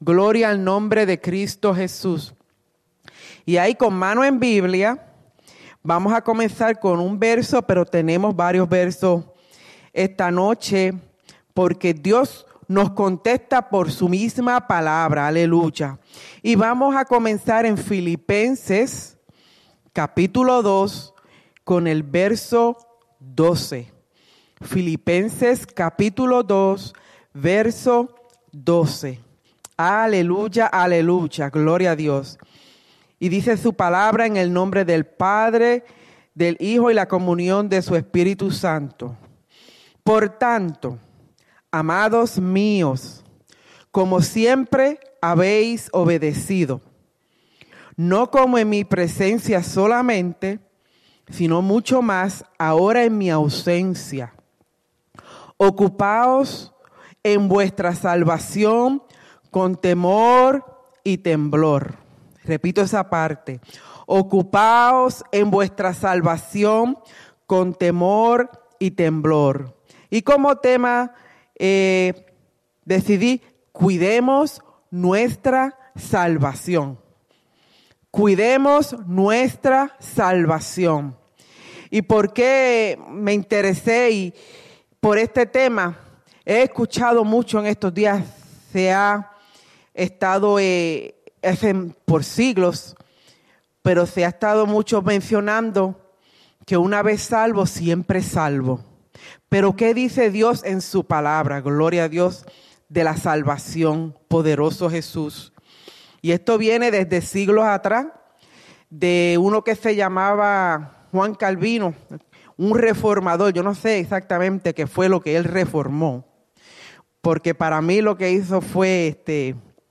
Mensaje
en la Iglesia Misión Evangélica en Souderton, PA